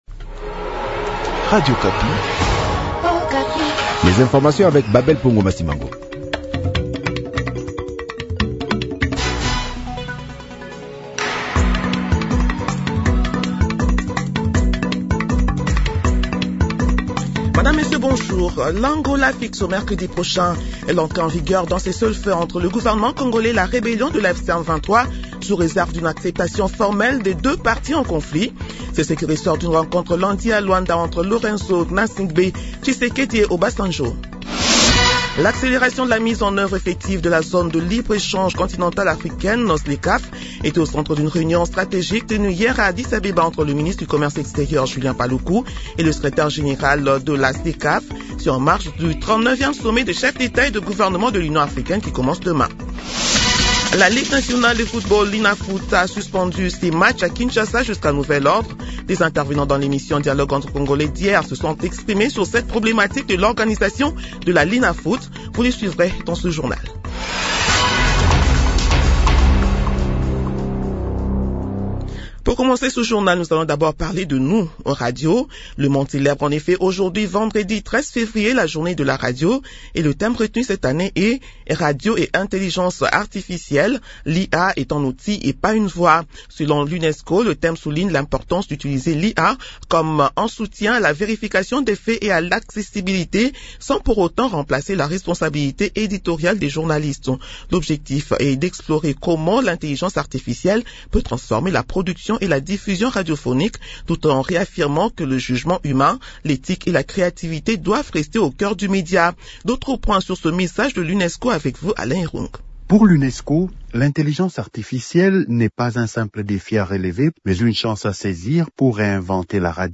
Journal matin 6 heures